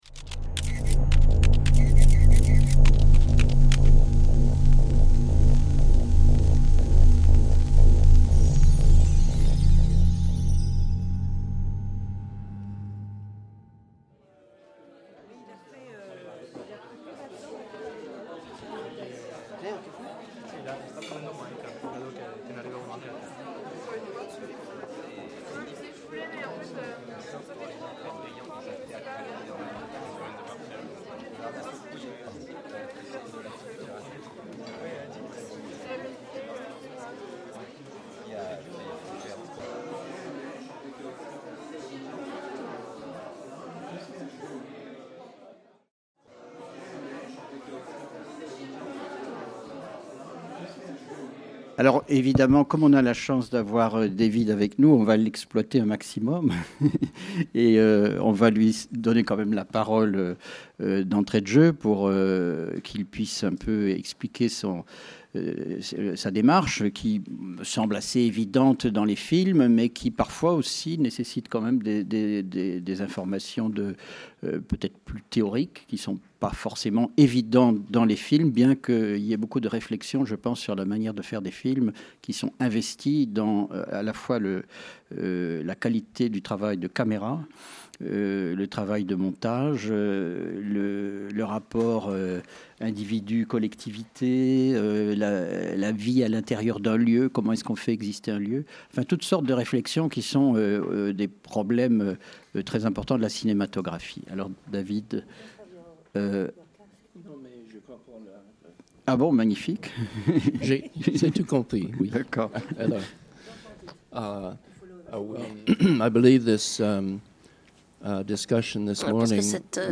1 - Table ronde (VF) - Hommage à David MacDougall | Canal U